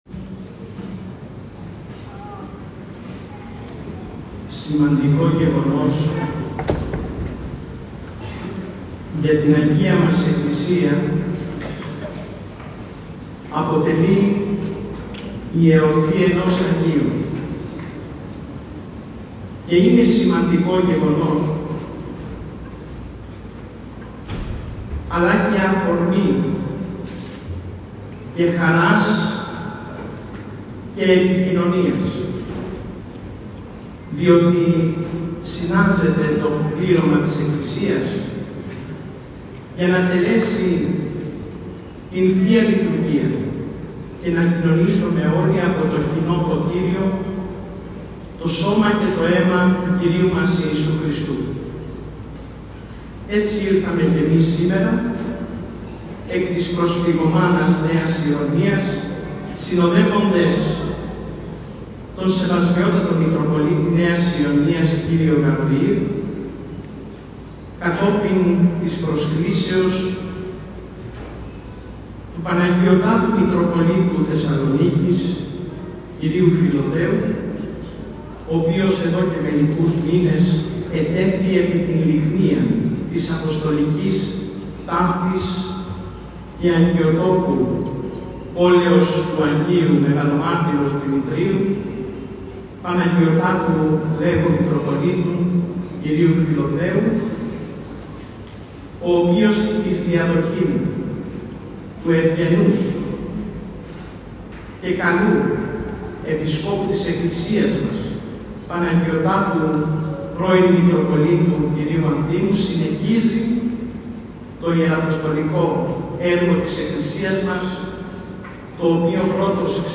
Δεκάδες πιστοί απο την ευρύτερη περιοχή, συμμετείχαν σήμερα το πρωί στην Δυσαρχιερατική Θεία Λειτουργία στον Ιερό Ναό του Προφήτου Ηλιού, προκειμένου να συμμετάσχουν στο μυστήριο της θείας ευχαριστιας και να λάβουν τη χάρη του Προφήτου.